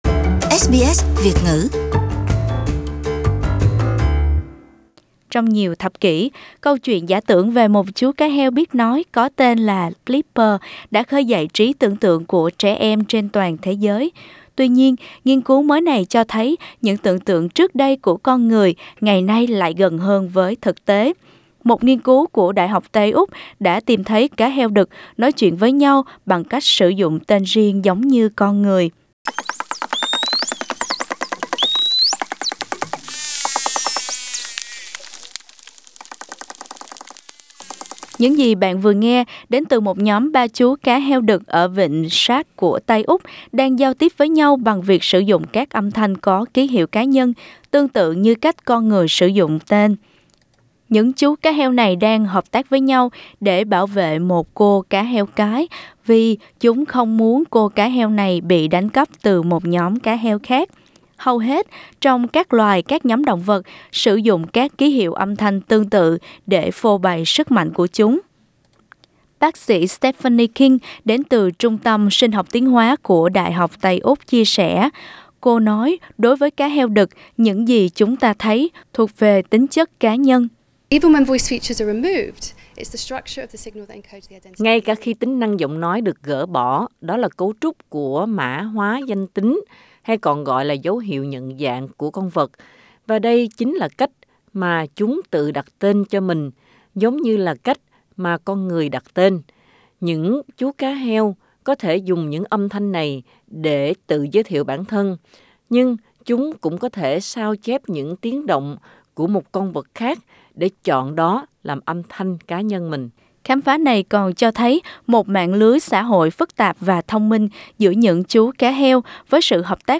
Những gì bạn đang nghe đến từ một nhóm ba chú cá heo đực ở Vịnh Shark của Tây Úc đang giao tiếp với nhau bằng việc sử dụng các âm thanh có ký hiệu cá nhân, tương tự như cách con người sử dụng tên.